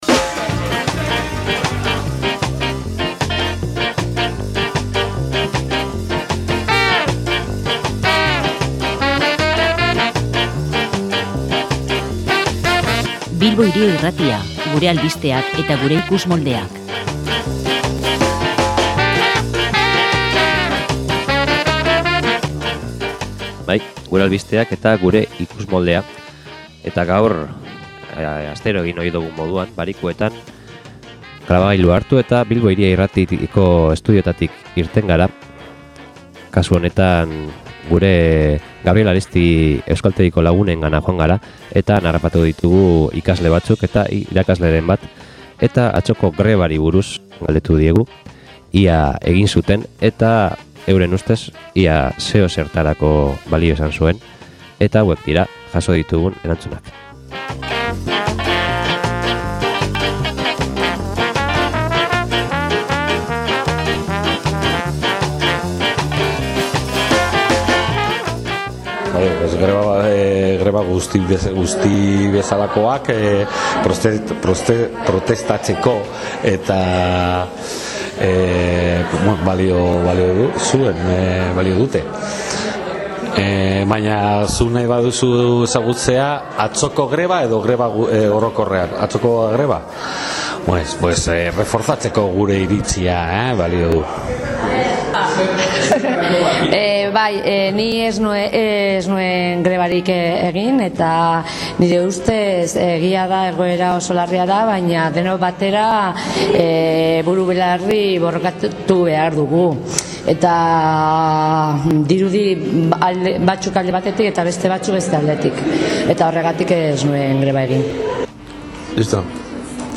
INKESTA
solasaldia